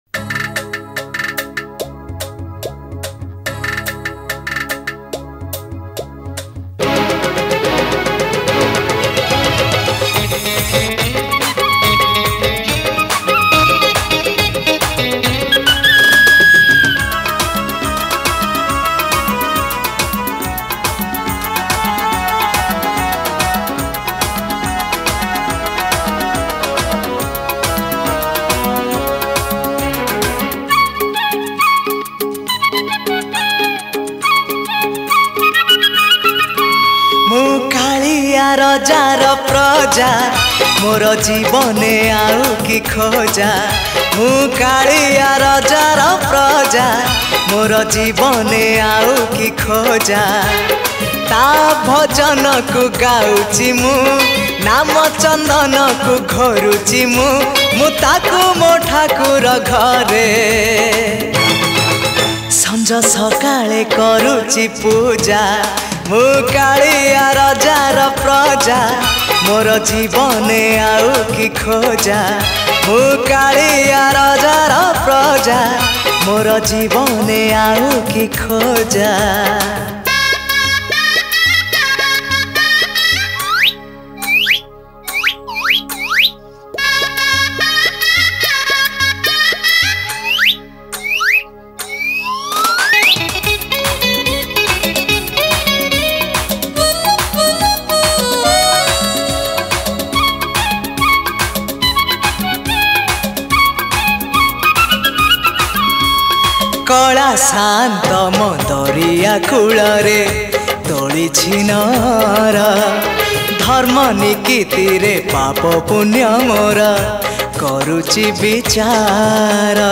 Bhajan